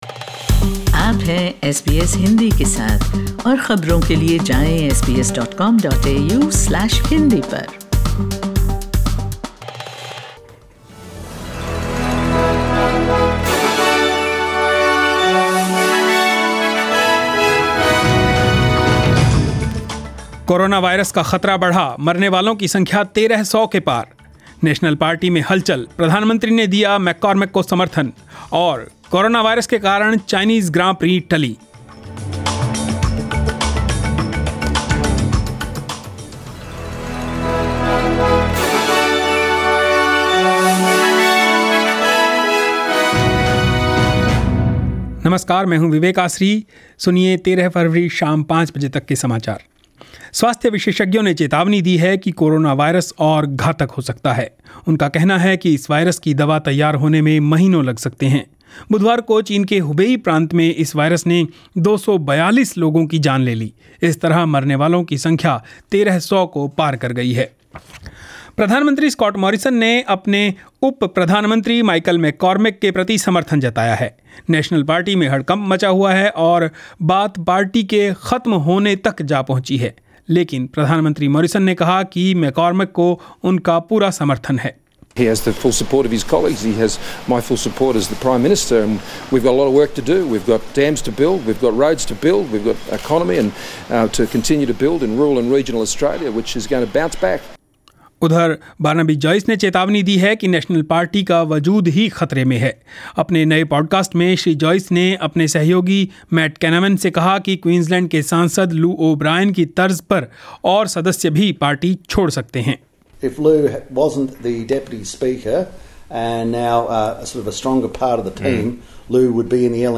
News in Hindi 13 Feb 2020